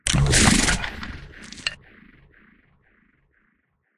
PixelPerfectionCE/assets/minecraft/sounds/mob/horse/skeleton/idle2.ogg at a6c9621b8715b0f669495c4e01fe0956a6951e64